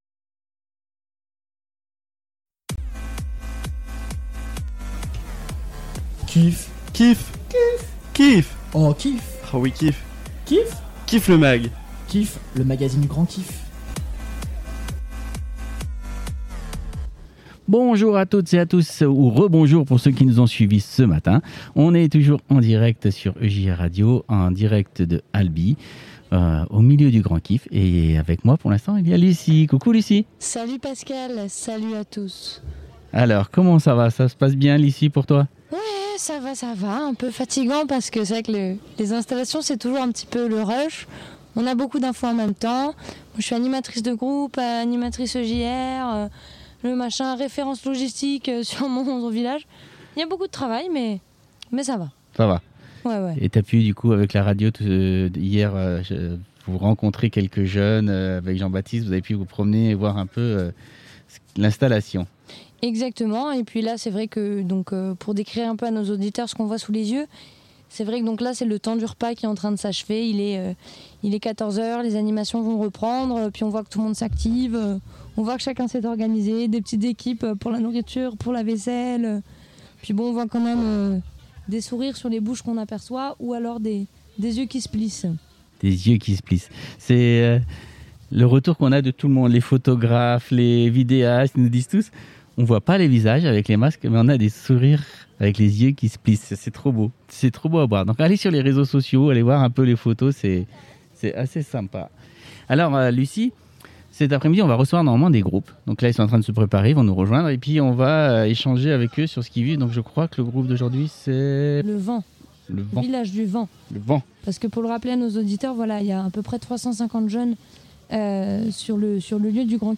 Le 3ème numéro du KIFFMAG en direct de ALBI le 30/07/2021 à 14h00